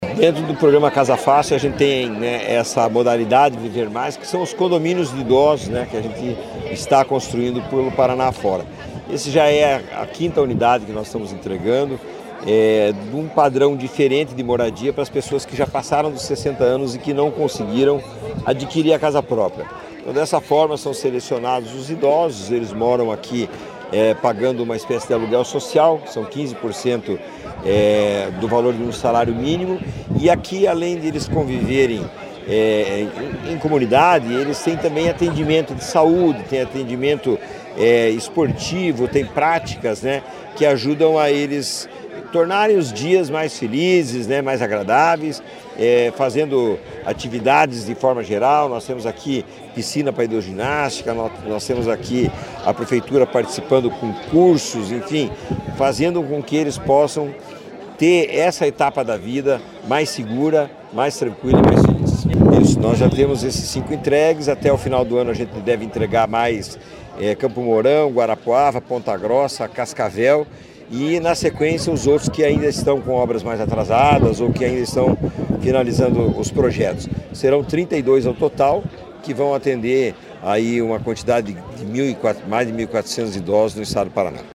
Sonora do presidente da Cohapar, Jorge Lange, sobre a entrega do Condomínio do Idoso de Arapongas